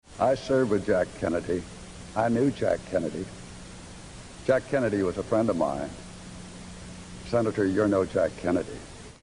During the vice-presidential debate on October 12, 1988, Lloyd Bentsen bristled at Dan Quayle’s self-comparison to JFK and replied with a crushing put-down.